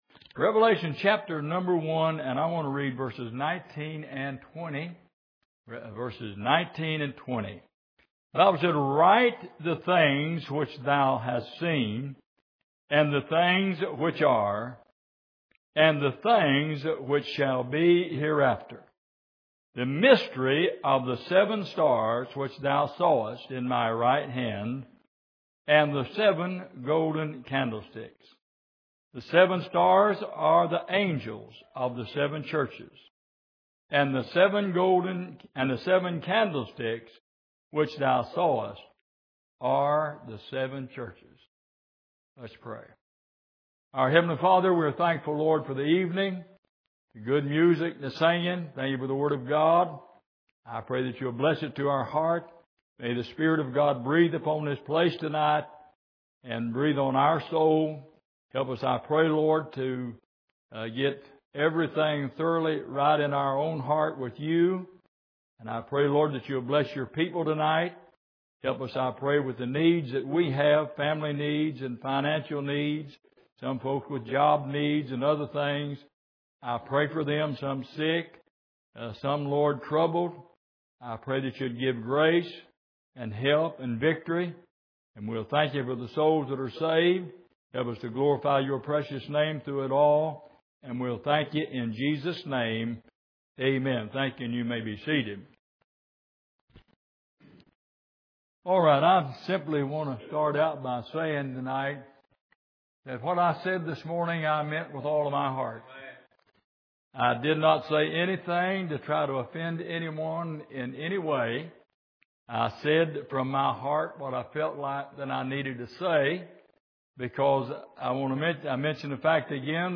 Revelation 1:19-20 Service: Sunday Evening A Knot In The Rope « Delight and Desires